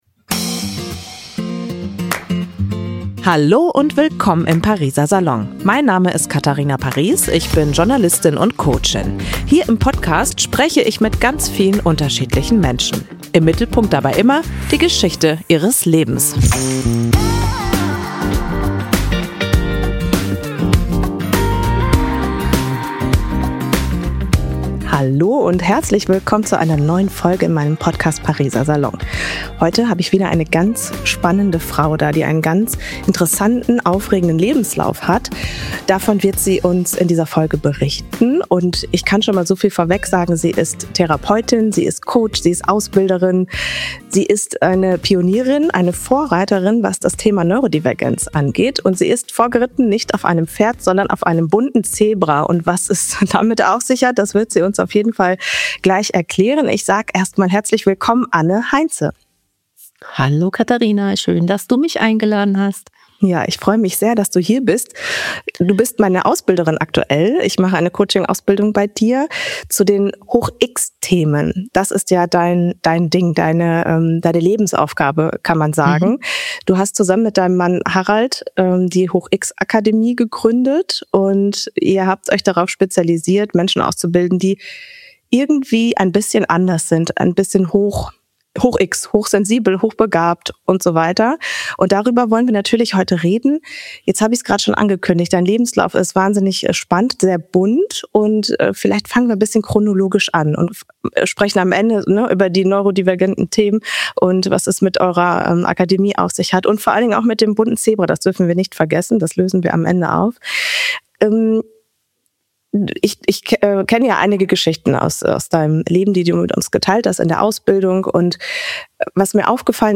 Außerdem sprechen wir darüber, welche Fragen wir uns stellen sollten, wenn wir an einem Punkt im Leben sind, an dem wir uns fragen: Was ist denn eigentlich meine Aufgabe hier im Leben? In diesem Gespräch ist wirklich sehr viel drin, es lohnt sich definitiv reinzuhören.